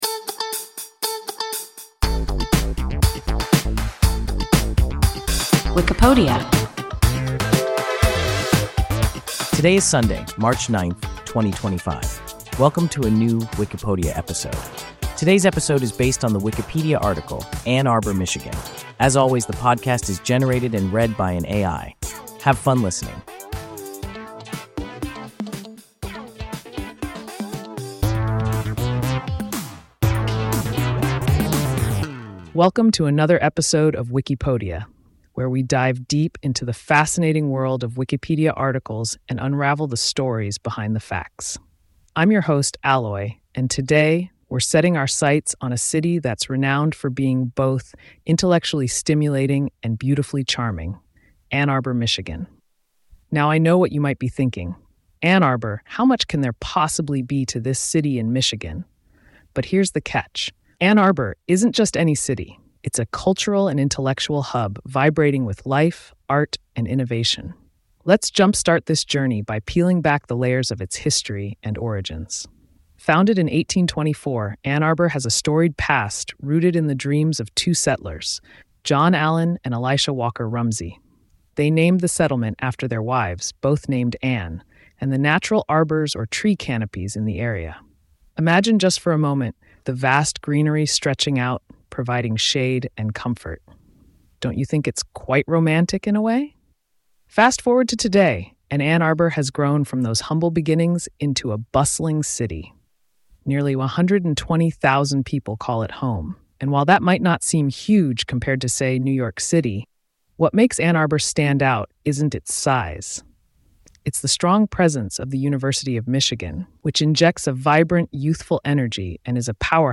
Ann Arbor, Michigan – WIKIPODIA – ein KI Podcast